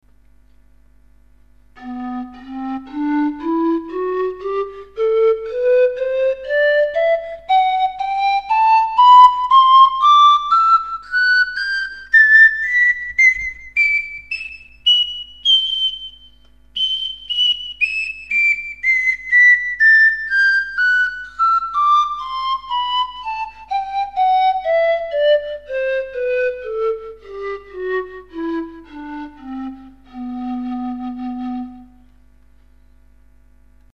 Gran Baritono Panflute b-g4 27 tubes
gran-baritono.mp3